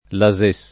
Lases [ la @%S ]